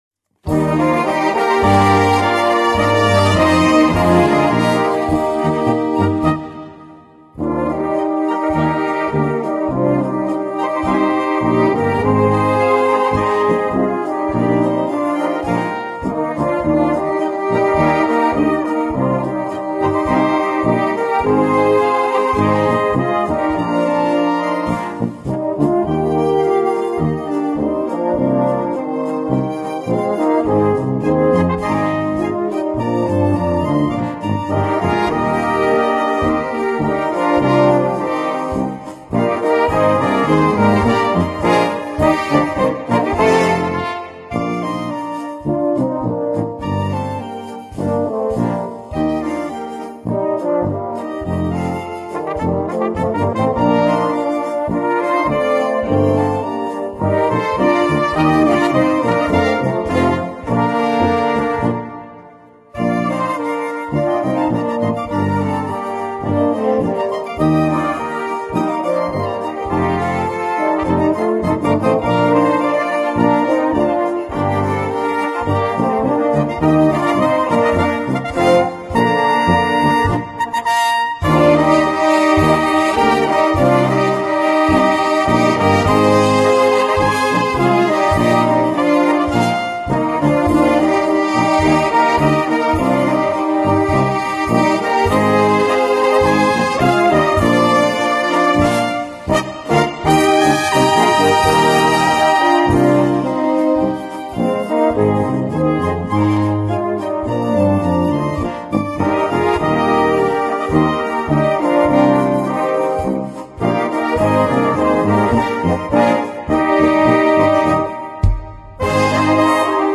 Gattung: Für 7er Besetzung
Besetzung: Ensemble gemischt